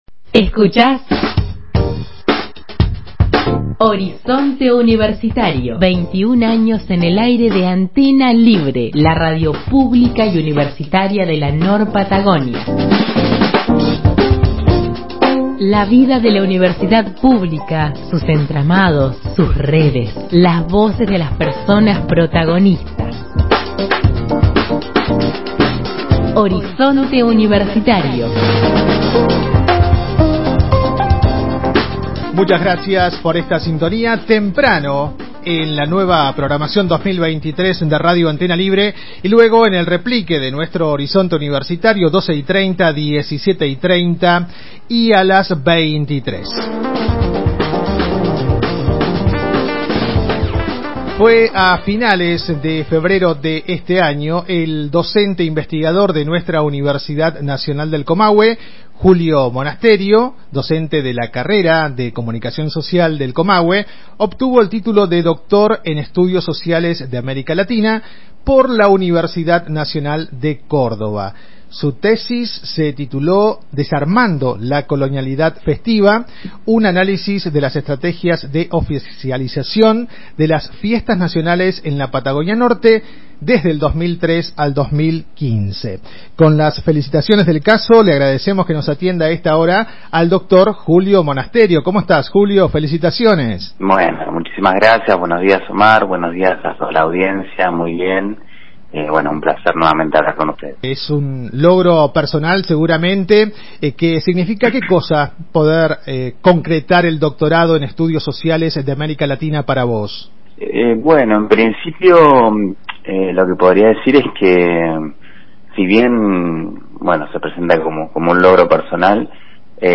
En diálogo con Horizonte Universitario